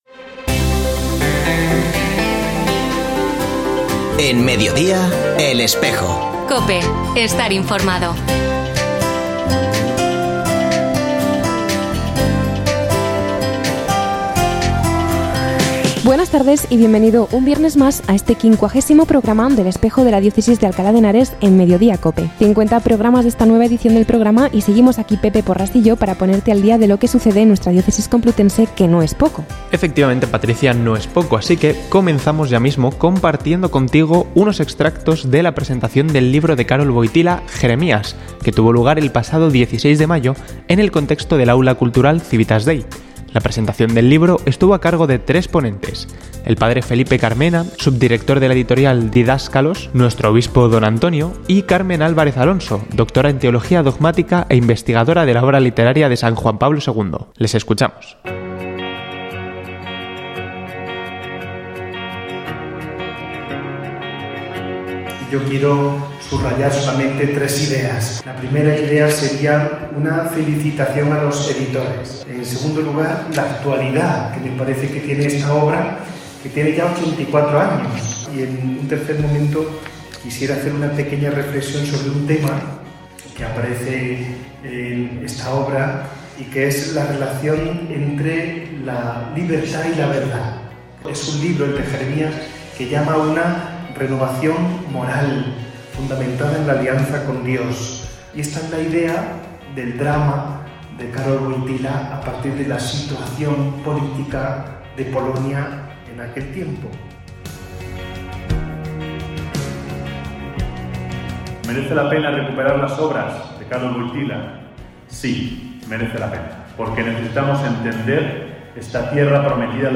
Ofrecemos el audio del programa de El Espejo de la Diócesis de Alcalá emitido hoy, 24 de mayo de 2024, en radio COPE. Este espacio de información religiosa de nuestra diócesis puede escucharse en la frecuencia 92.0 FM, todos los viernes de 13.33 a 14 horas.